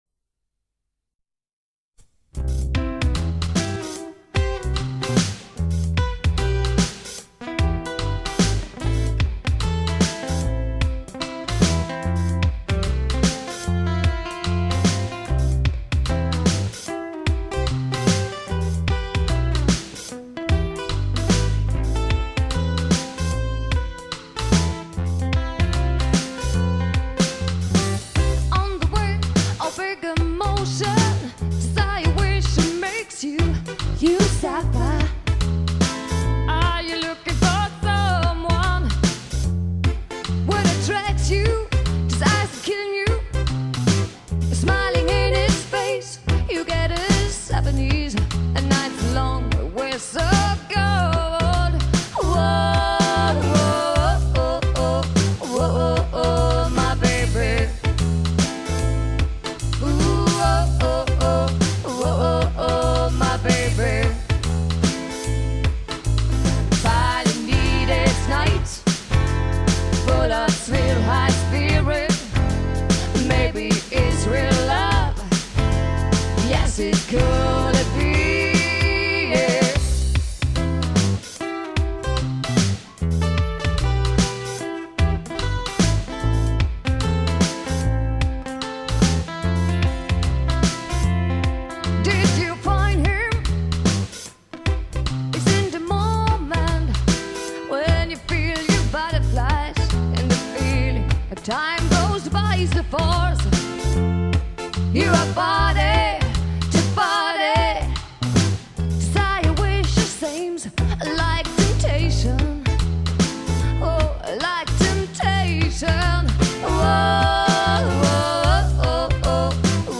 bass
guitar, vocal
vocal, percussion
drums